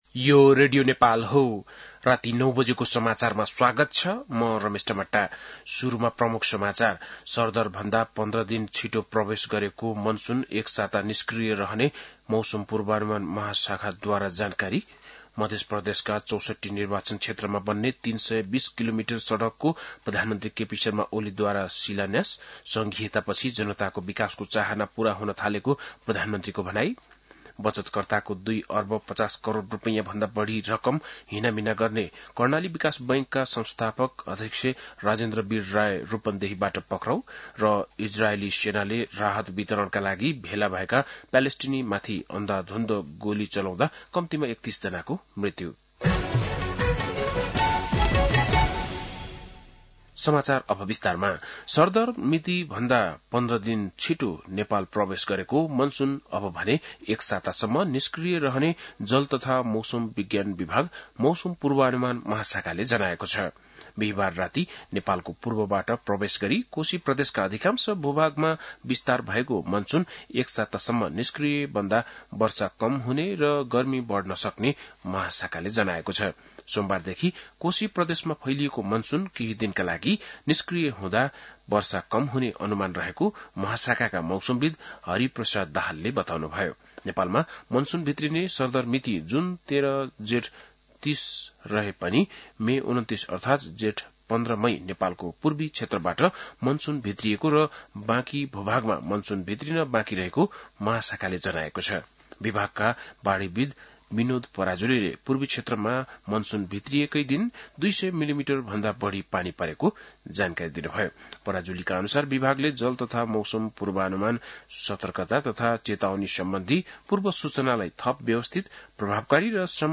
बेलुकी ९ बजेको नेपाली समाचार : १८ जेठ , २०८२
9-pm-nepali-news-.mp3